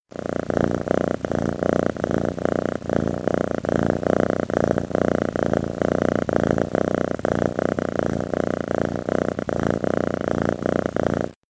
Katt Purr (Ljudeffekter)
Djur , Katter